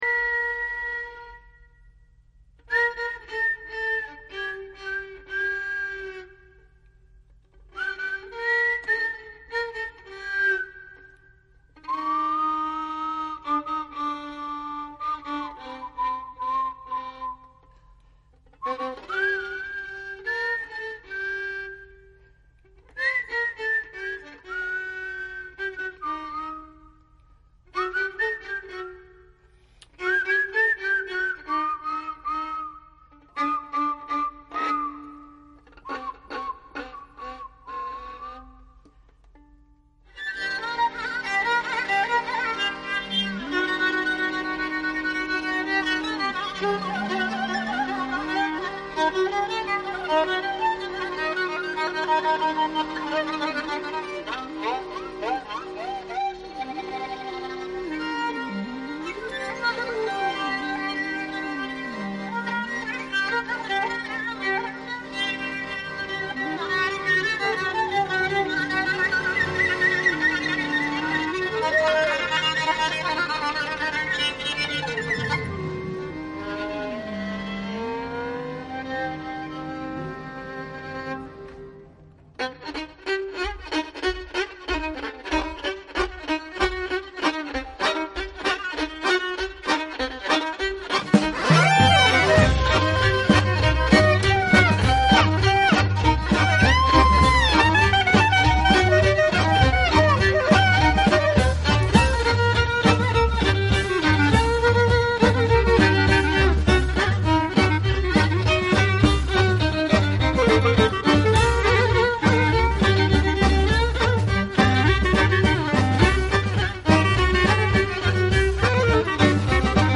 Klezmer music in the style of Moldavian klezmer